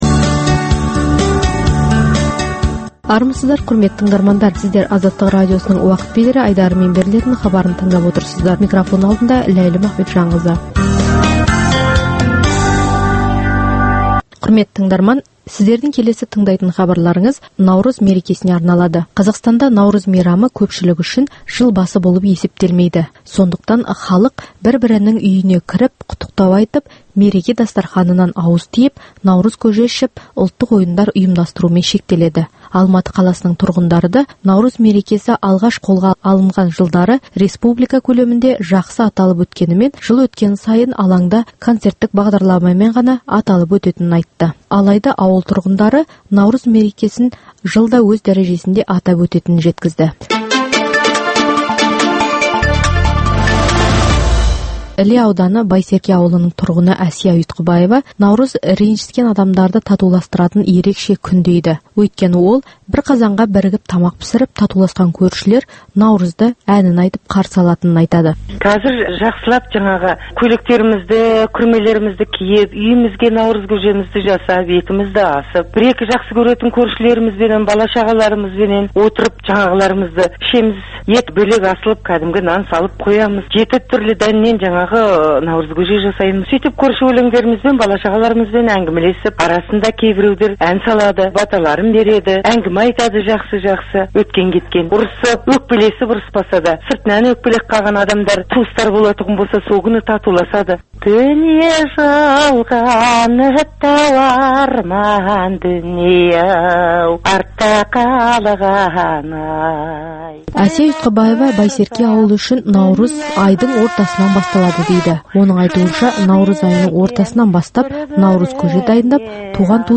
Бүгінгі "Уақыт бедері" айдарында Азаттық тілшілері хабарласқан азаматтар өздерінің Нурыз мерекесін қалай тойлайтындықтарын әңгімелеп Наурыз әндері мен Наурыз баталарын да мерекелік көңіл-күймен айтып берді. Қазақстанда Наурыз мейрамын көп жұрт жыл басы деп есептемейді.